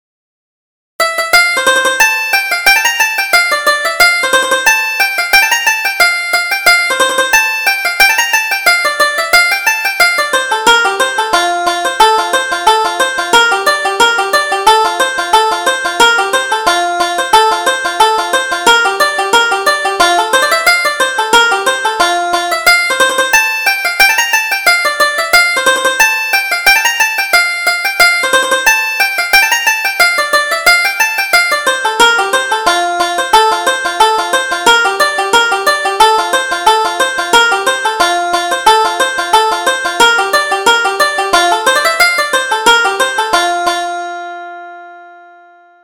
Reel: The Blue Garters